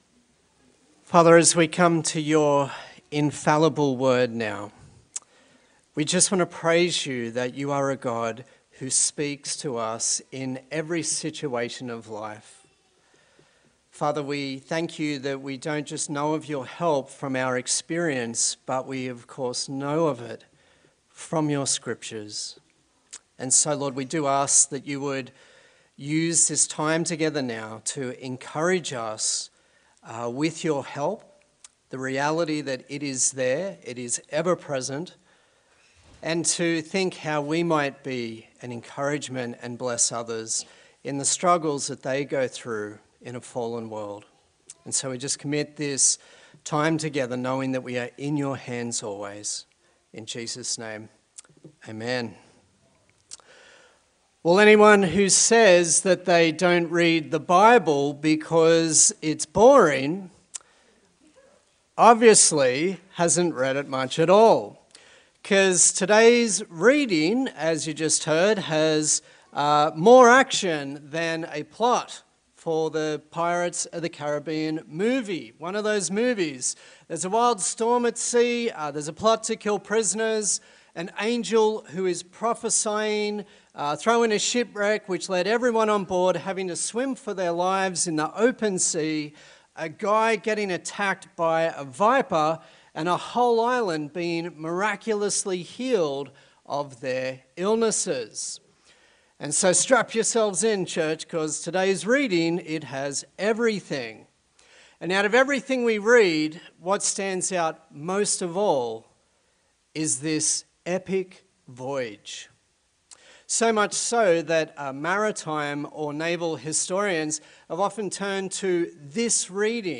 Acts Passage: Acts 27:1-28:10 Service Type: Sunday Service